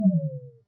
level-lost.mp3